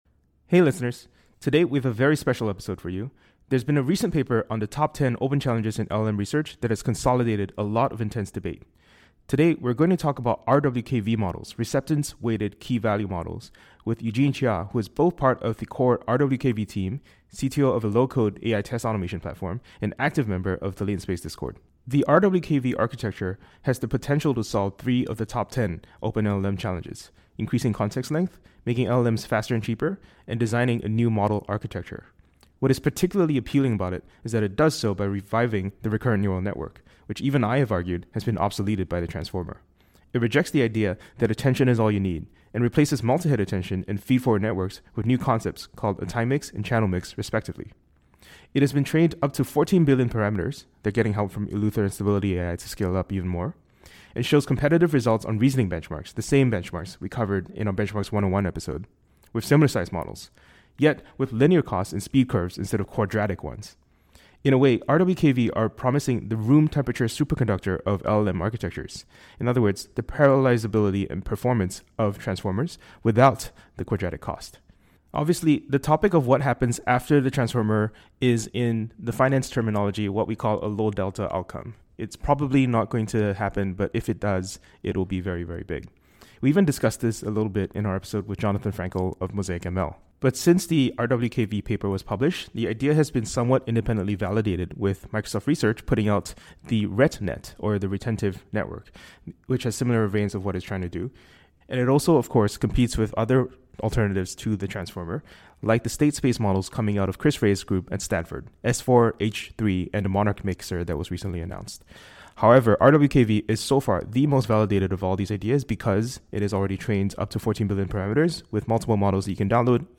The conversation highlights the significance of community-driven AI resources and how RWKV addresses memory limitations in processing large datasets.